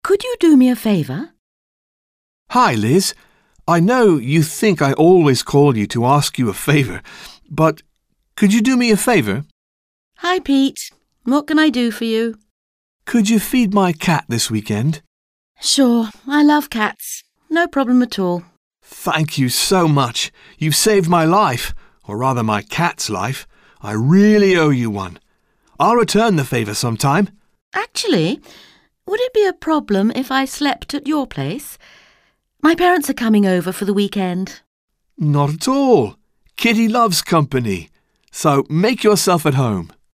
Dialogue - Could you do me a favour?